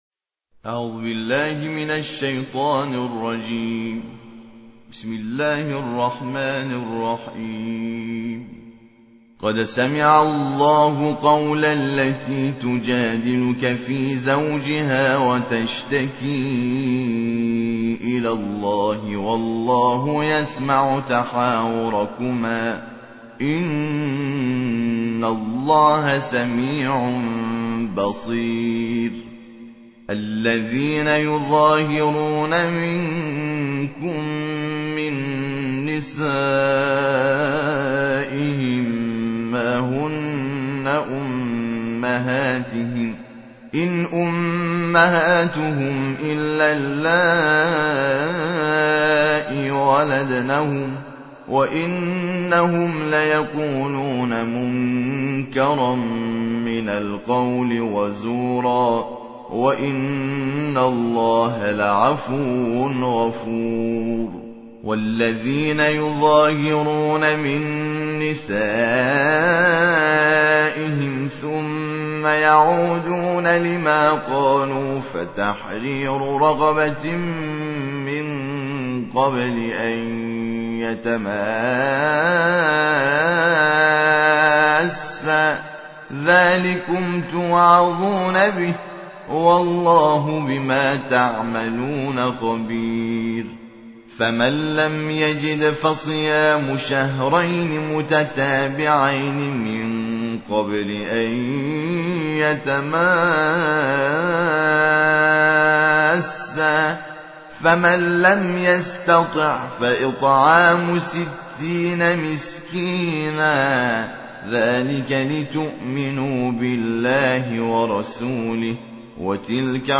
ترتیل جزء بیست و هشتم قرآن کریم - حامد شاکر نژاد با ترافیک رایگان